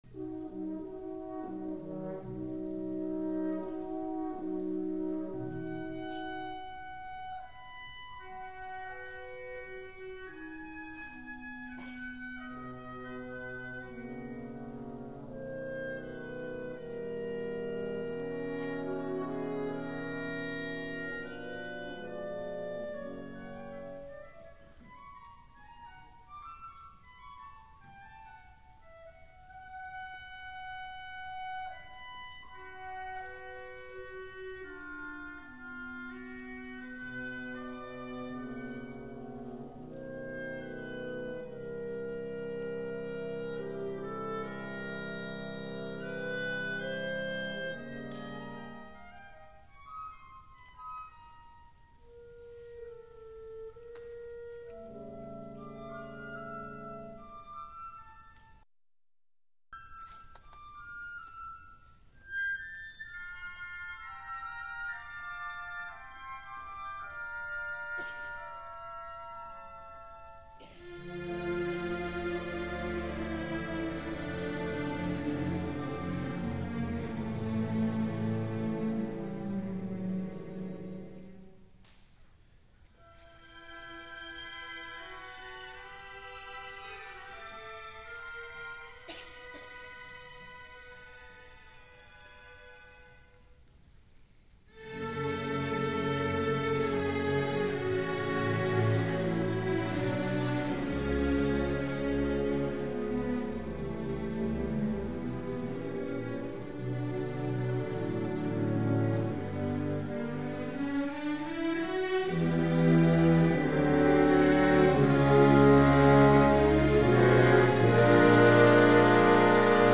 名手達　　その５：　木管とチェロ　　１９９２年
この演奏では、管楽器のソロが素晴らしい。偶然、録音のマイクが遠く、遠近感が上手く出ているせいもある。クラリネットやフルートのソロは安心して聴くことができる。
バックを支える中低音金管のサウンドも暖かくて良い。
それにしても、録音がやたらマイクオフで、しかも、会場の雑音が多いのはちょっと不思議。実況中継風なのは良いのだけれども、お客の？おしゃべりまで良く入っている。